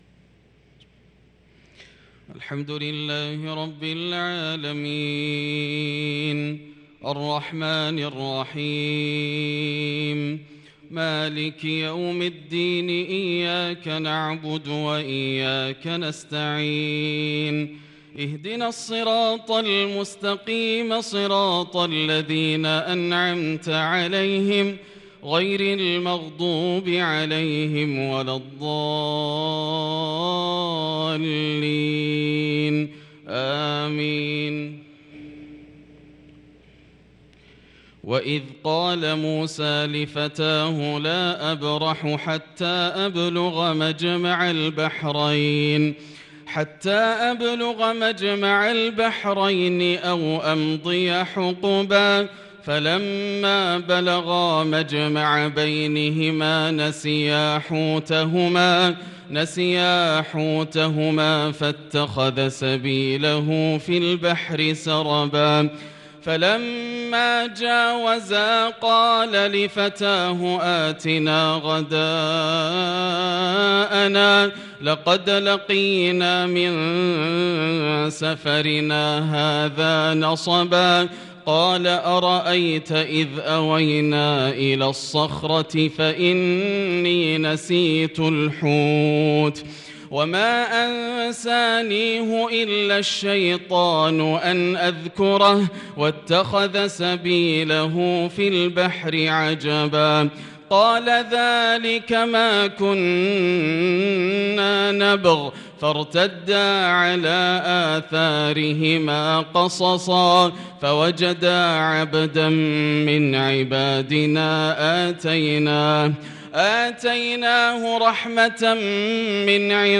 صلاة الفجر للقارئ ياسر الدوسري 25 شوال 1443 هـ
تِلَاوَات الْحَرَمَيْن .